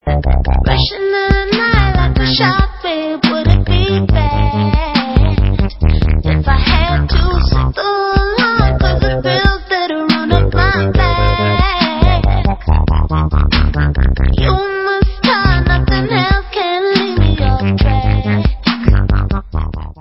sledovat novinky v oddělení Dance/Breakbeats